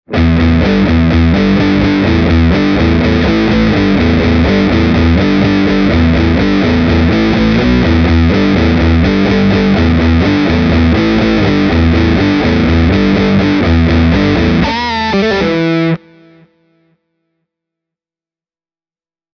Putting good descriptions to sounds is always rather hard – so take a listen to the soundbites I recorded for you, using my Fender ’62 Telecaster Custom -reissue, as well as my Hamer USA Studio Custom. All delays and reverbs have been added at during mixdown.
Hamer – fat distortion
hamer-e28093-fat-distortion.mp3